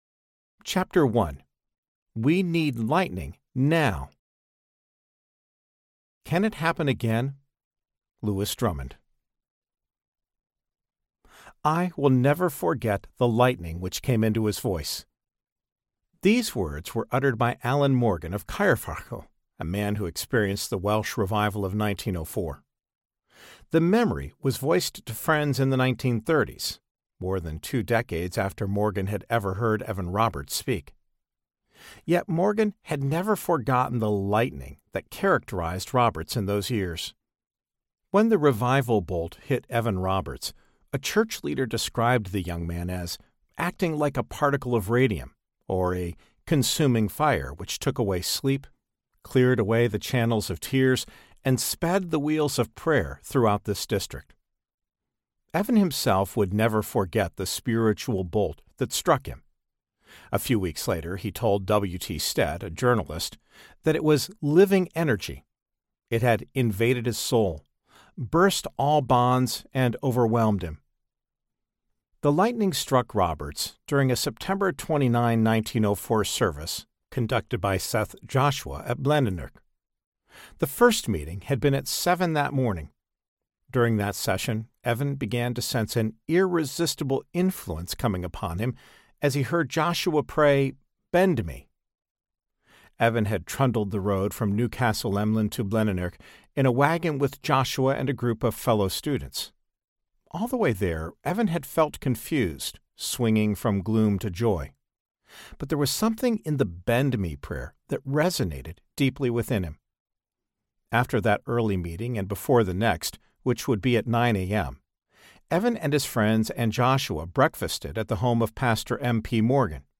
Call Down Lightning Audiobook
Narrator
7.08 Hrs. – Unabridged